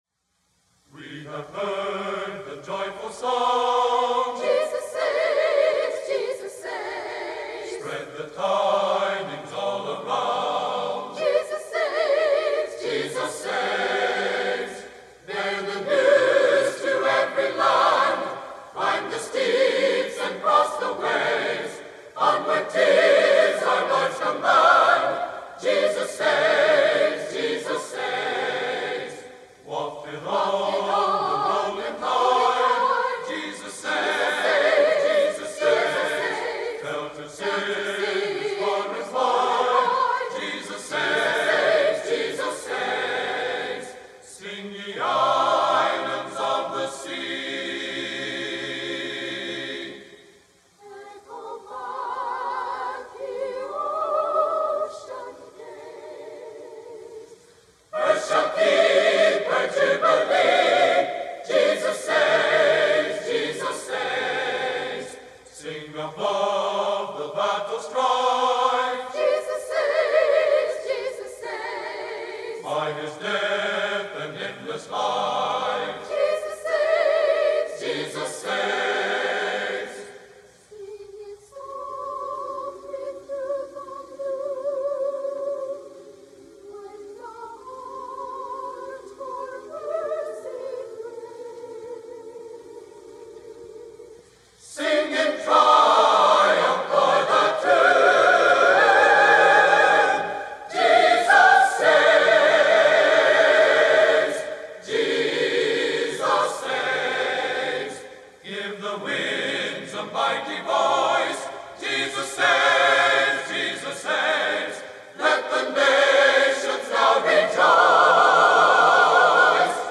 Bethany Nazarene College A Cappella Choir Sings - 50th Anniversary Choir at Pilot Point, Texas
A Cappella Choir